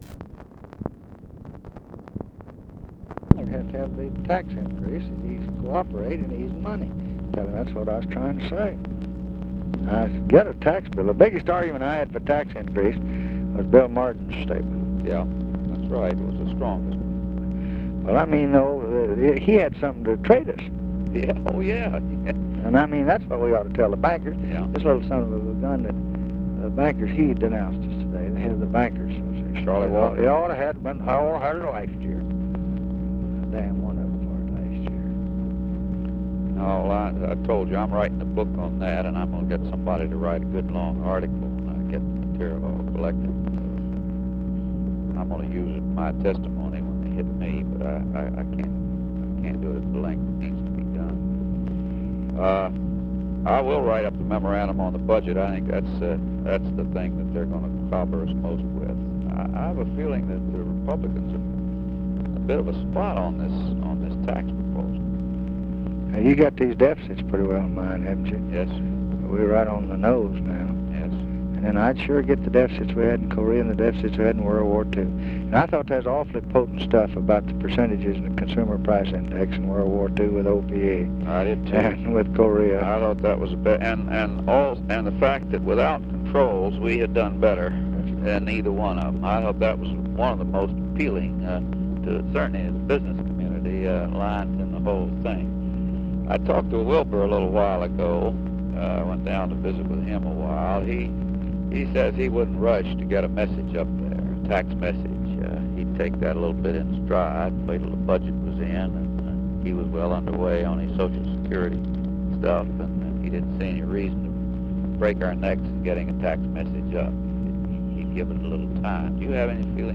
Conversation with HENRY FOWLER, January 11, 1967
Secret White House Tapes